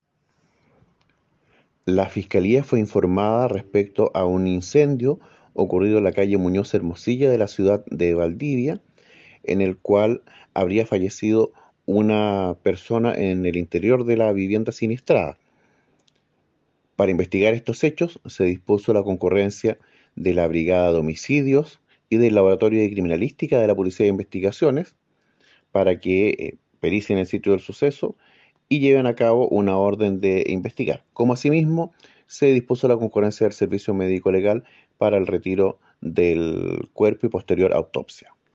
fiscal Gonzalo Valderas sobre un incendio en el que falleció una persona hoy en Valdivia